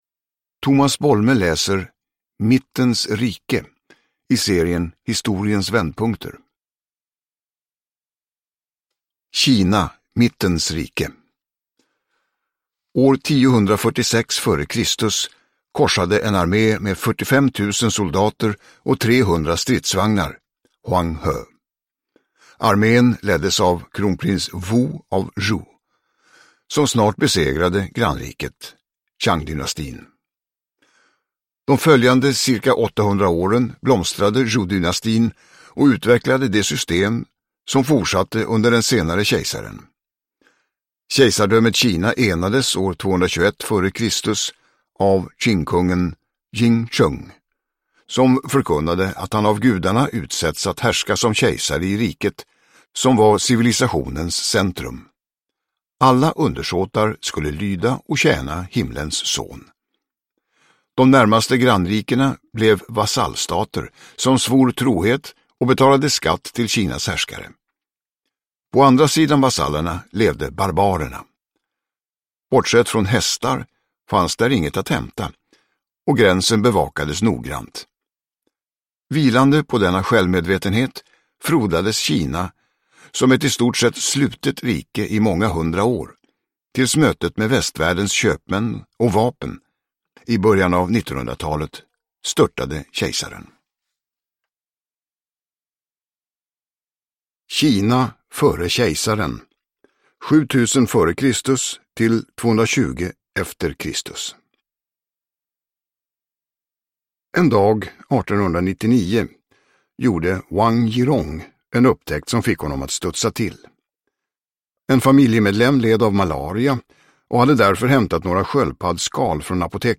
Mittens rike – Ljudbok
Uppläsare: Tomas Bolme